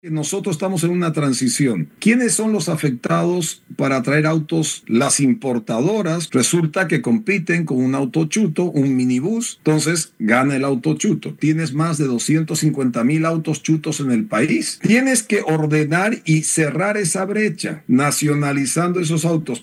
Por otra parte, Rodrigo Paz fue entrevistado en Radio Marítima Digital de Bolivia y tuvo fuertes declaraciones en contra de Carabineros, indicando que son incompetentes porque no pueden proteger su frontera e, incluso, cuestionando que sean ellos participes de los robos de automóviles en Chile, para después ponerlos a la venta, en colaboración con mafias bolivianas.